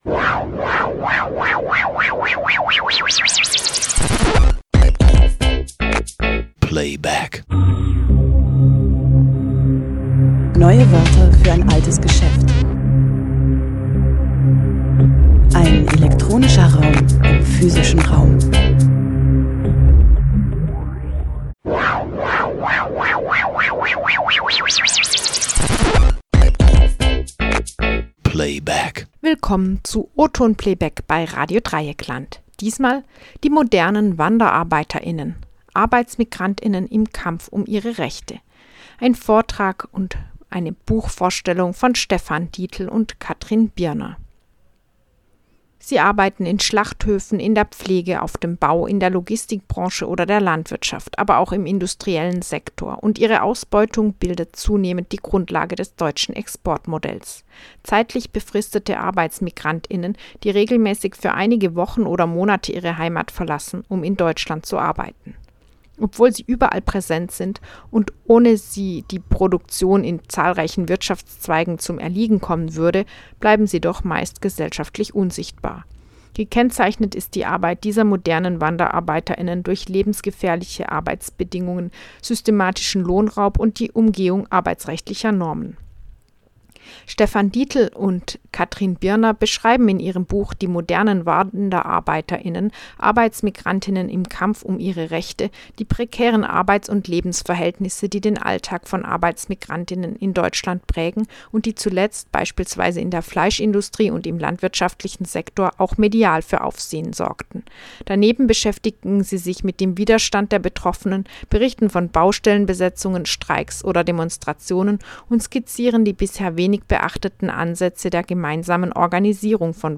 Buchvorstellungen